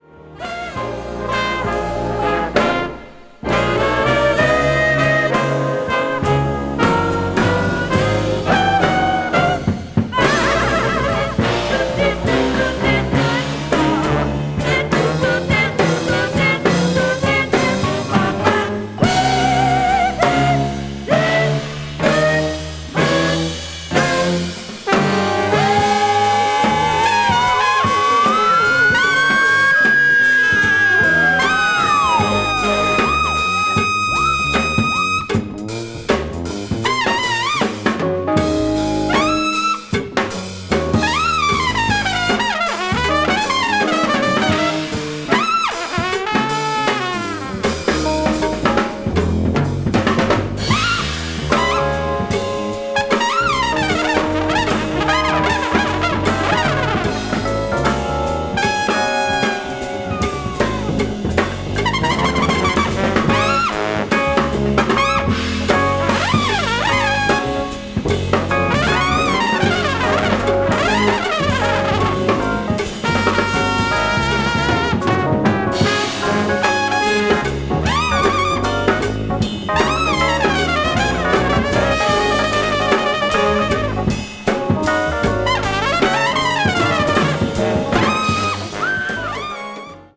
media : EX/EX,EX/EX(わずかにチリノイズが入る箇所あり)